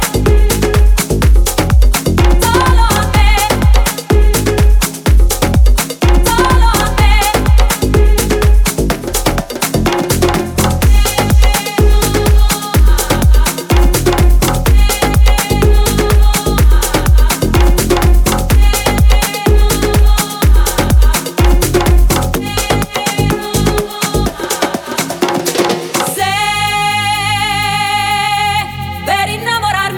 Жанр: Танцевальные / Поп